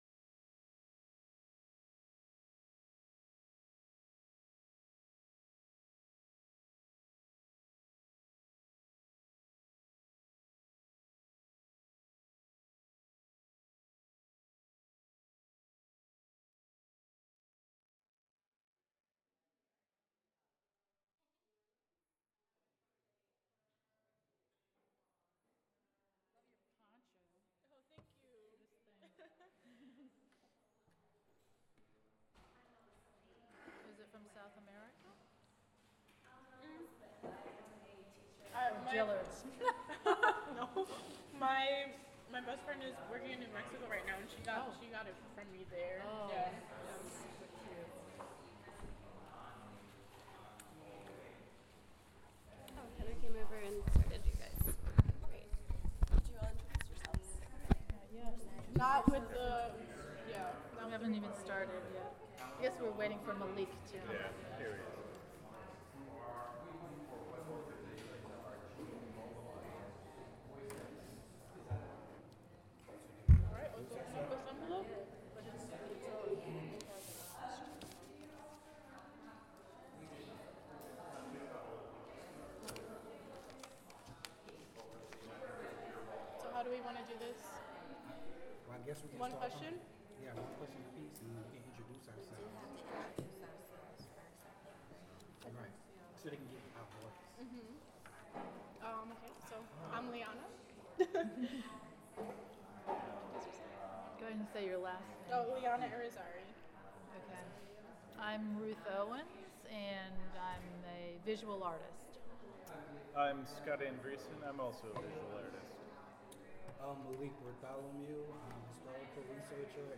Type sound recording-nonmusical
Genre oral history